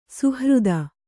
♪ suhřda